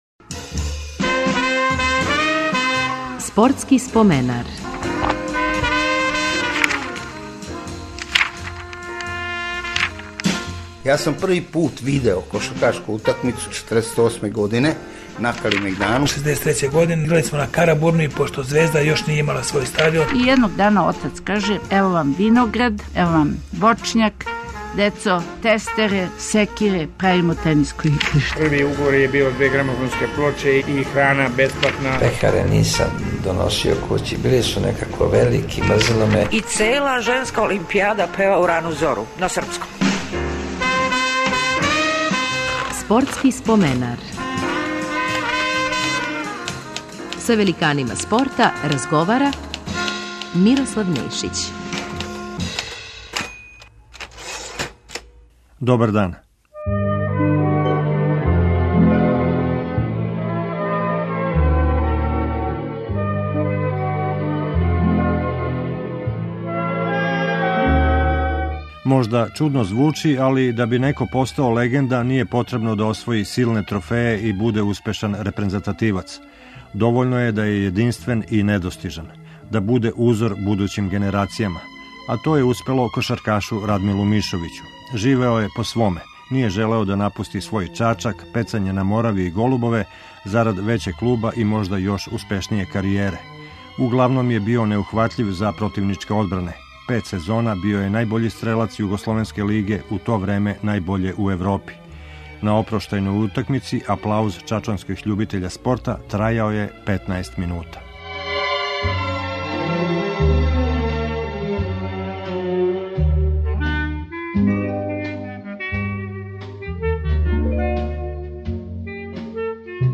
Гост емисије биће кошаркаш Радмило Мишовић. Остао је упамћен као један од најбољих кошгетера свог доба.